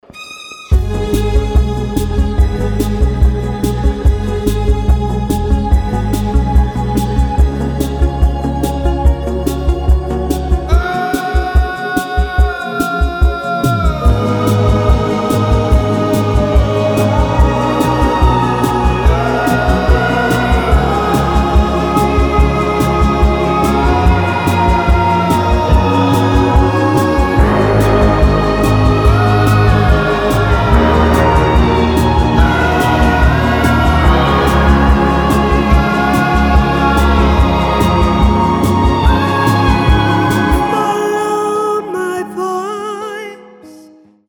• Качество: 320, Stereo
chillout
alternative
Alternative, dream pop во всей красе